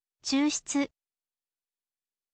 chuushutsu